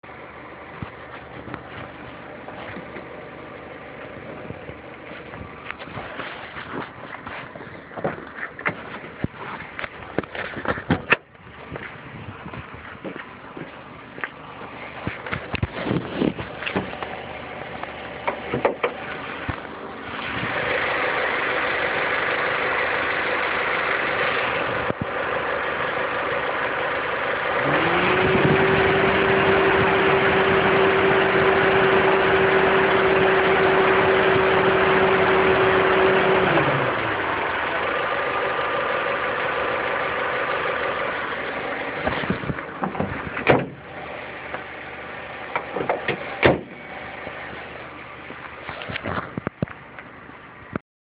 Mahlzeit, höre seit kurzem öfters mal son komisches klackern ausm Motorraum(wenn man aussteigt und sich daneben stellt) hab ja schon mal was von den Hitzeblechen gehört vom EP, gibts sowas beim EM2 auch ?
Hab auch mal ne Soundfile gemacht, so von 20 - 28 Sekunde, denk mal man hörts relativ schlecht :/ hab irgendwie das Gefühl die Kiste hört sich nich gesund an...
so grob gepielt würd ich das nach den tönen eher vorsichtig bei dem genagel auf ein verstelltes ventilspiel schieben.
in der zeit von 20-28 halt ich das handy direkt überm motorblock wo ich auch das gefühl hab das es von da kommt